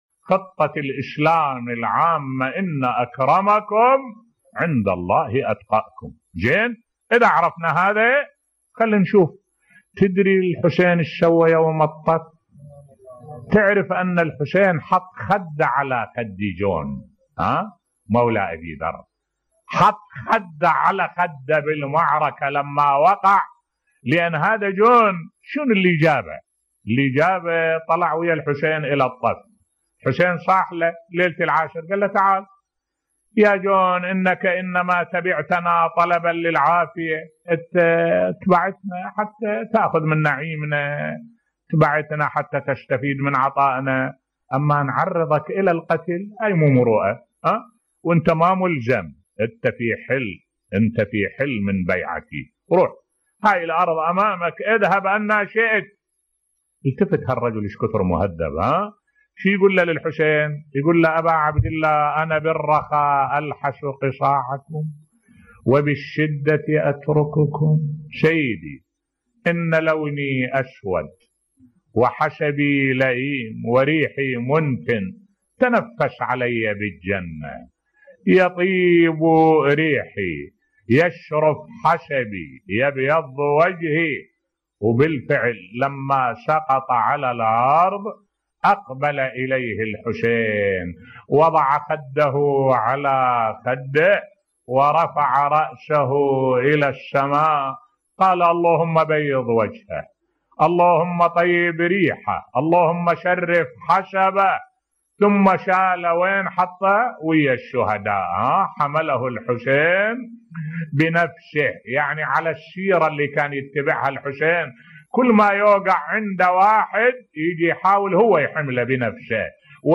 ملف صوتی تكريم الامام الحسين للشهيد جون بصوت الشيخ الدكتور أحمد الوائلي